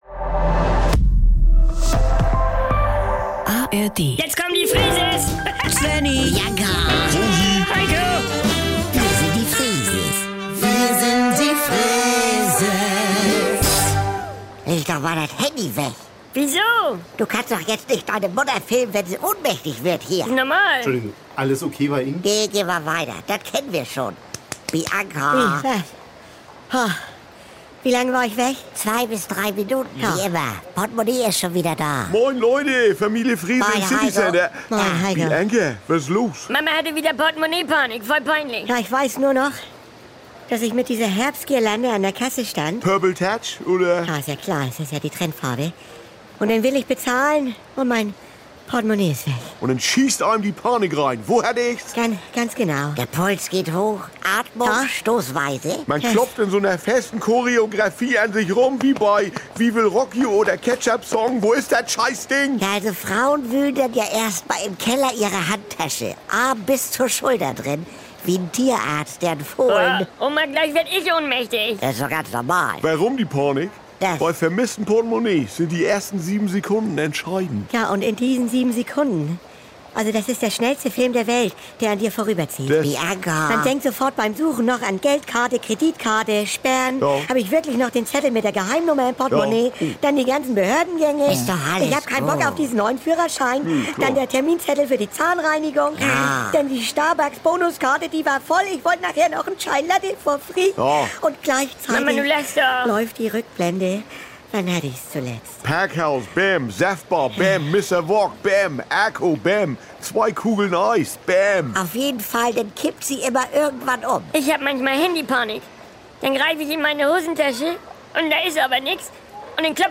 Saubere Komödien Unterhaltung NDR 2 Komödie NDR Freeses Comedy